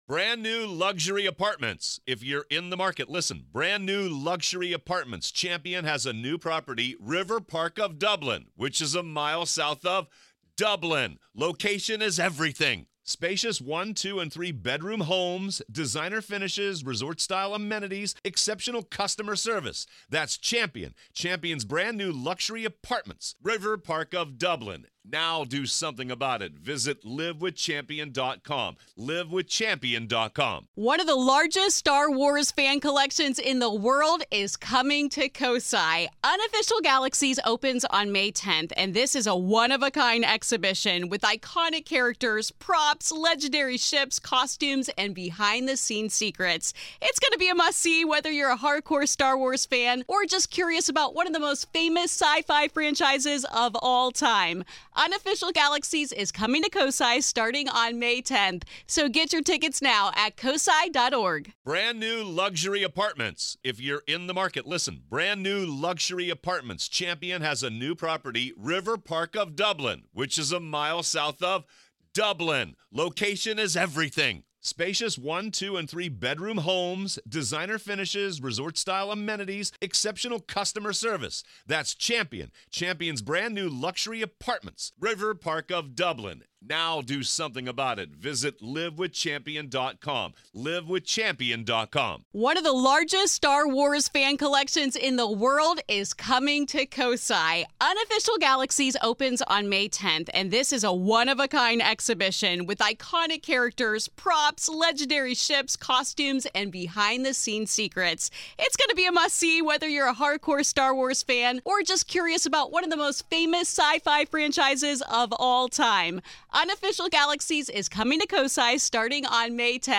Discover what demons truly want, how they affect our world, and most importantly, how to protect ourselves from falling victim to their malicious presence. Prepare for an eye-opening conversation on the dangers of the demonic realm and the ways we can defend against it.